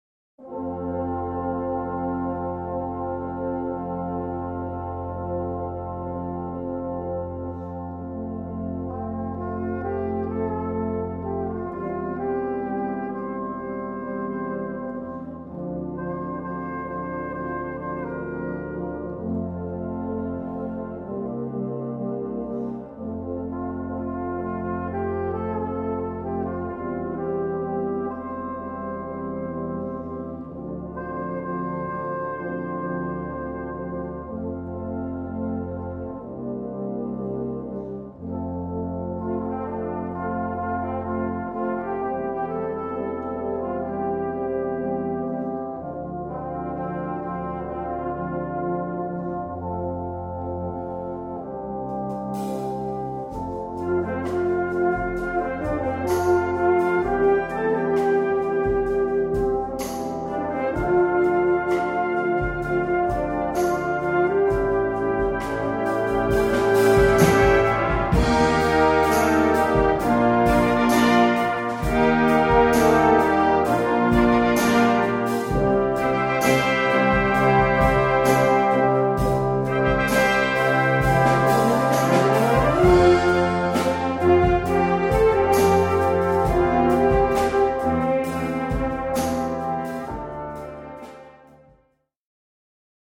Gattung: Moderner Einzeltitel
Besetzung: Blasorchester
Ein sehr emotionaler Song